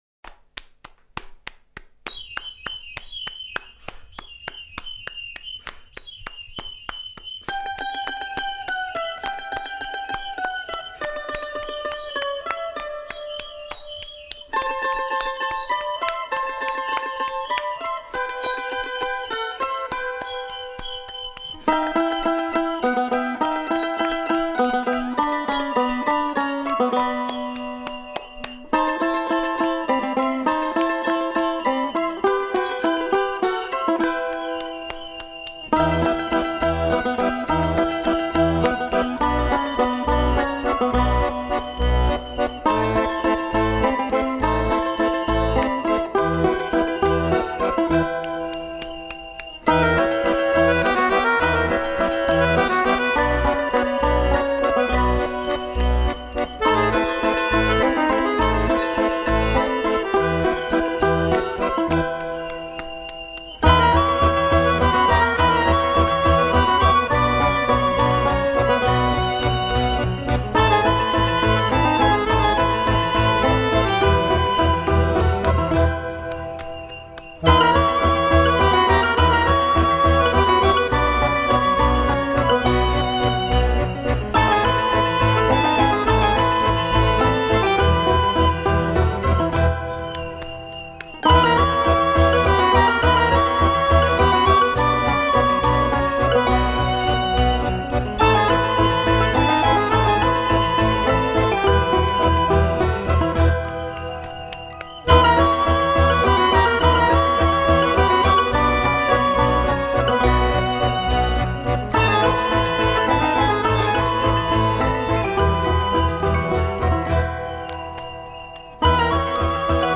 音乐类型:Original Soundtrack
层层叠叠的钢琴、小提琴、手风琴、鼓声、口琴
主旋律带出一次次变奏，旋律就这般悠然荡漾。